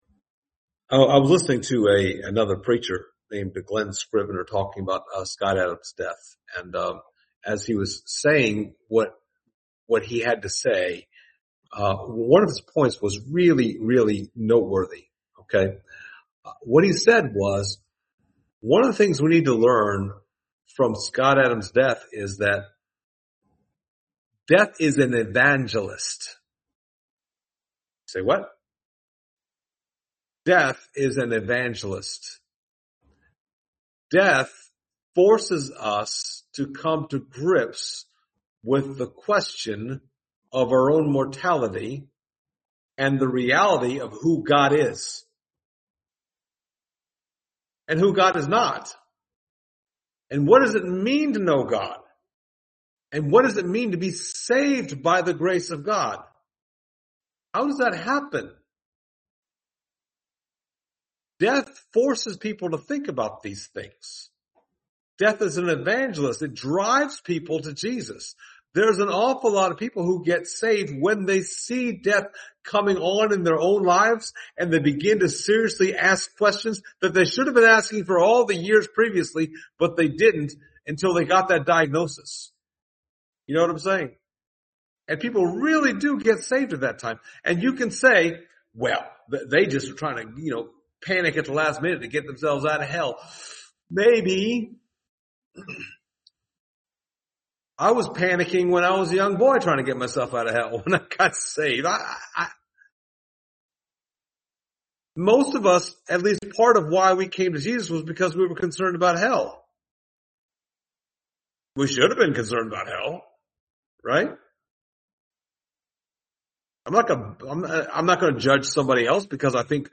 Service Type: Sunday Morning Topics: community , shared reasures